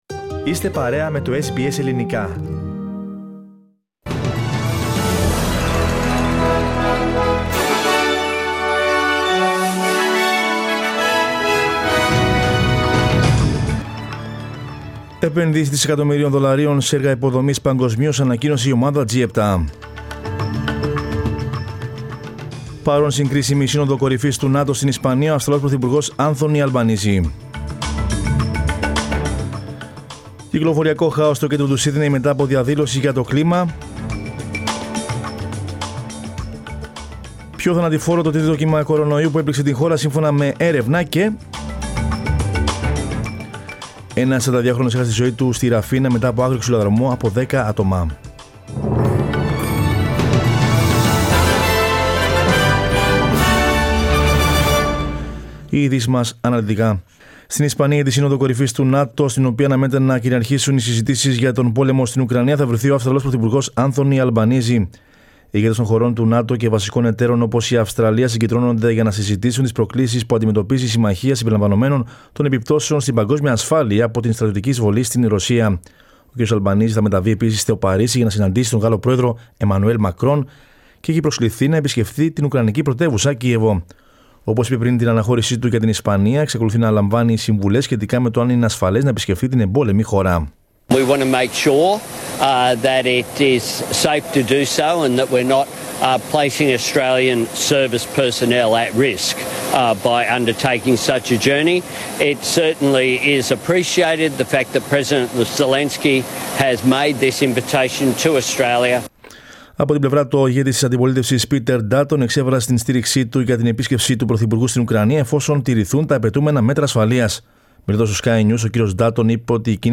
Δελτίο Ειδήσεων Δευτέρα 27.06.22
News in Greek. Source: SBS Radio